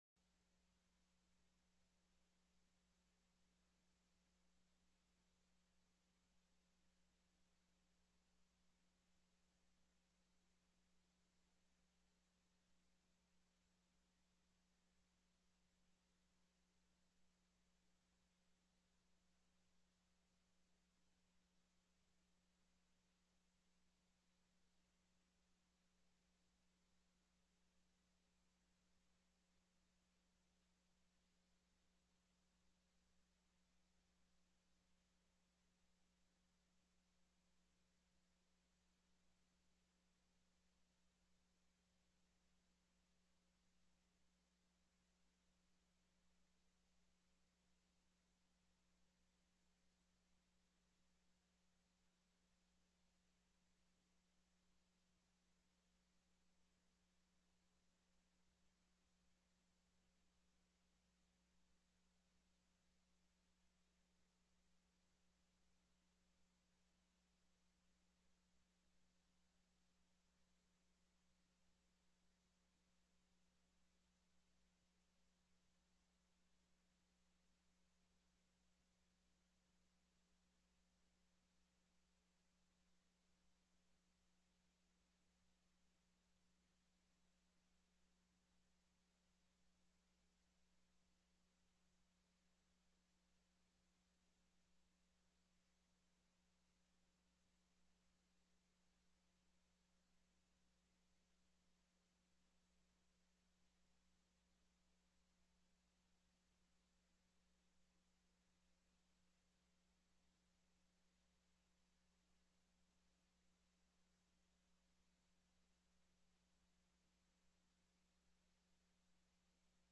03/24/2021 01:30 PM House JUDICIARY
The audio recordings are captured by our records offices as the official record of the meeting and will have more accurate timestamps.
TELECONFERENCED
Public Testimony